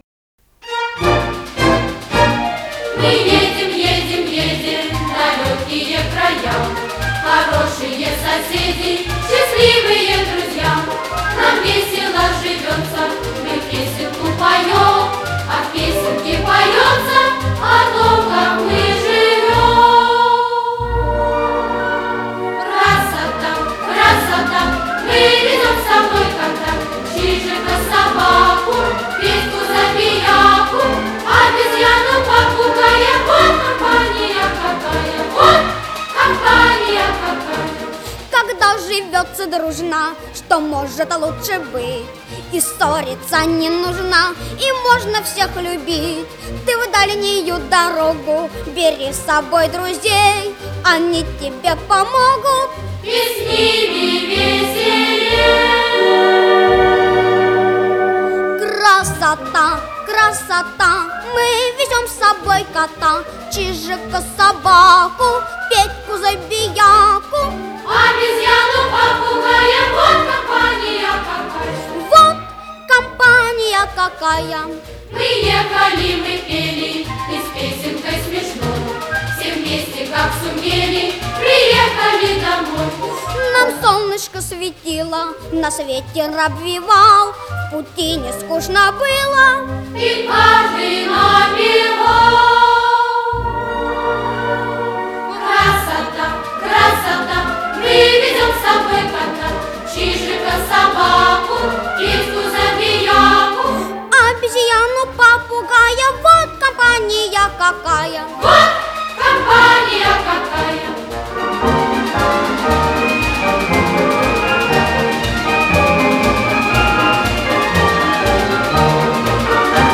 Искал песню "Веселые путешественники (детский хор), которую предположительно в свое время с него скачивал.